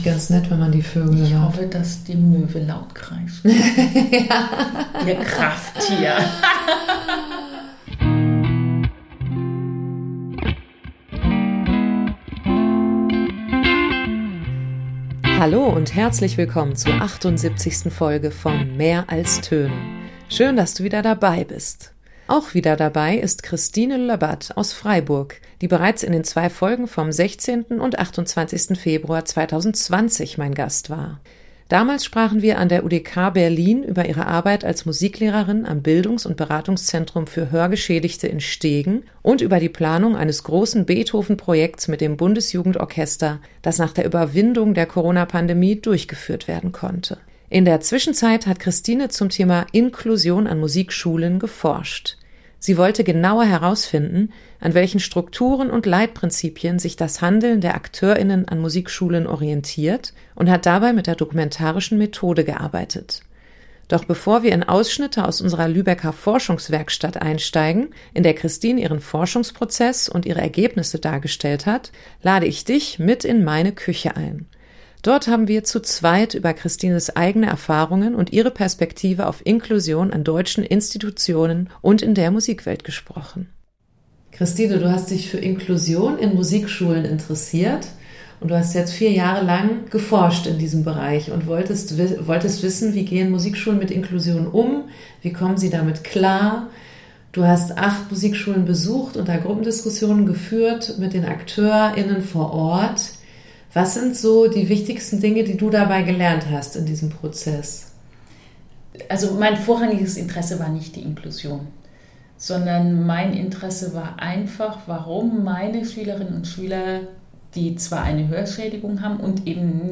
in meiner Küche. Während im Hintergrund die Möwen kreischen